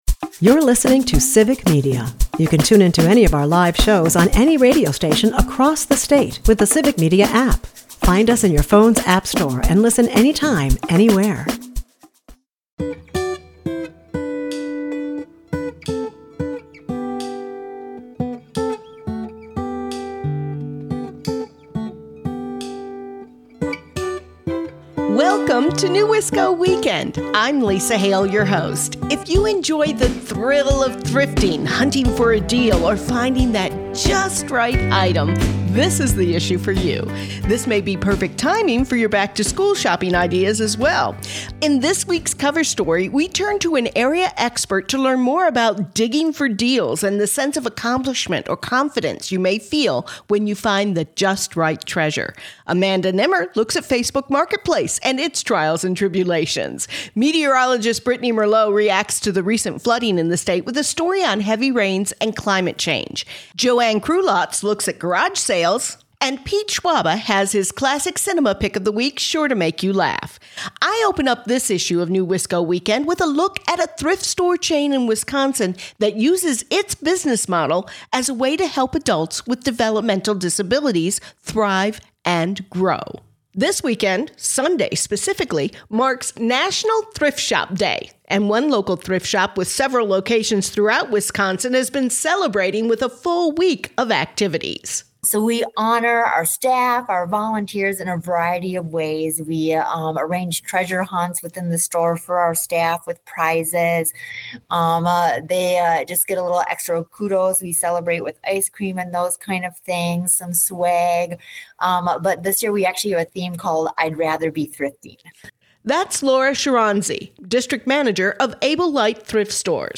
NEWisco Weekend is a part of the Civic Media radio network and airs Saturdays at 8am & Sundays at 11am on 98.3 and 96.5 WISS and Sundays at 8 am on 97.9 WGBW .